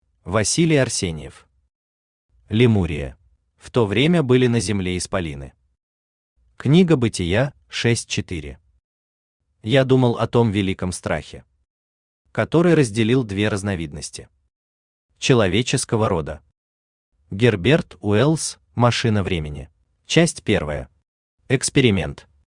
Aудиокнига Лемурия Автор Василий Арсеньев Читает аудиокнигу Авточтец ЛитРес.